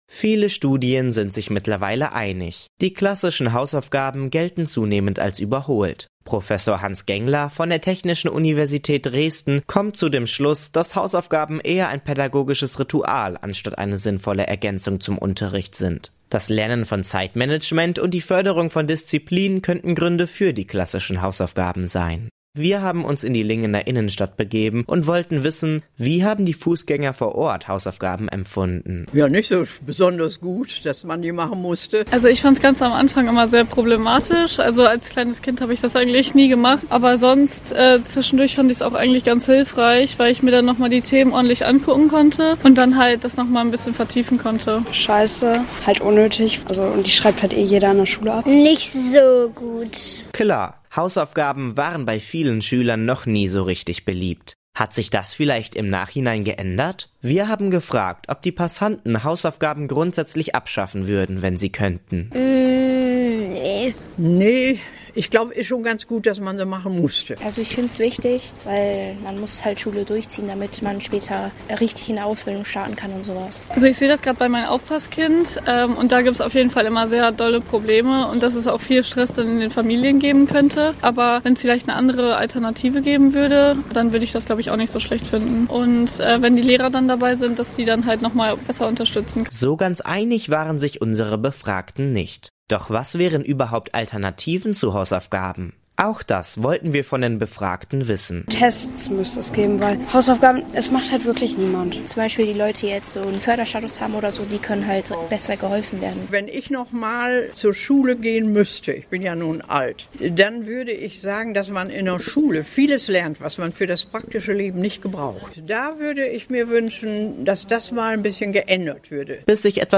Umfrage: Sollten Hausaufgaben abgeschafft werden?